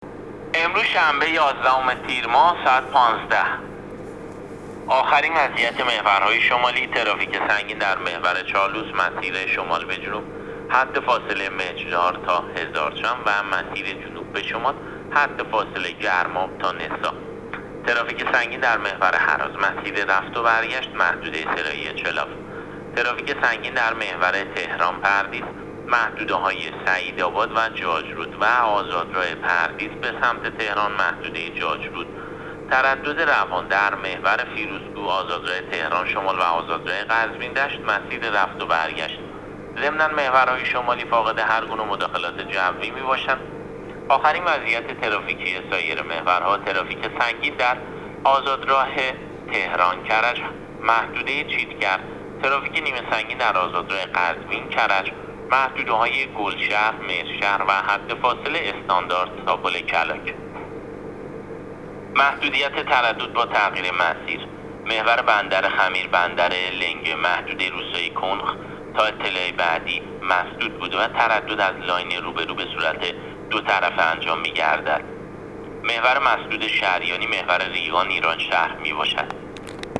گزارش رادیو اینترنتی از آخرین وضعیت ترافیکی جاده‌ها تا ساعت ۱۵ یازدهم تیر؛